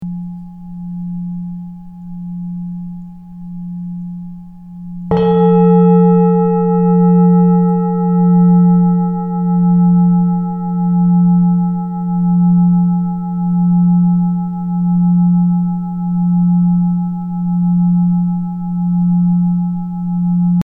resonance-extrait-2-calice-or.mp3